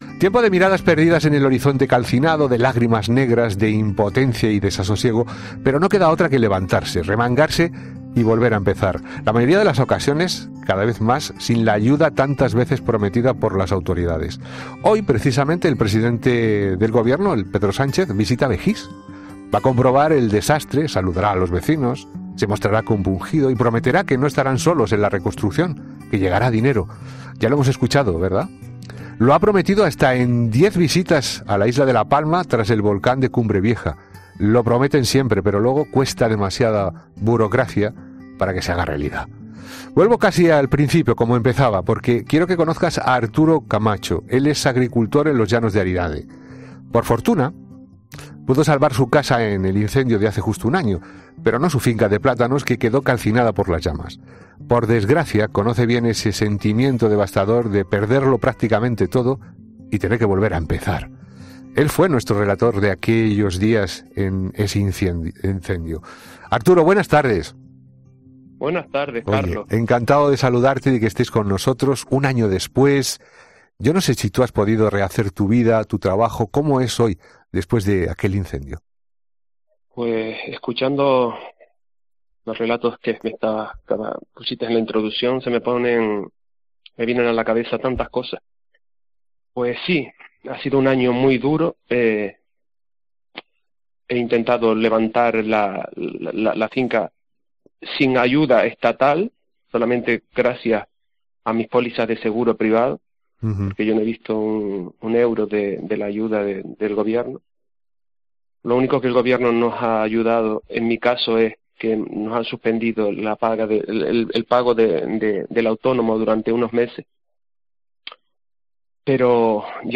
Todas se han registrado en la mitad sur de la isla canaria. 00:00 Volumen Descargar Vecinos afectados por la erupción cuentan su actual situación - ¿Puede haber otra erupción en España?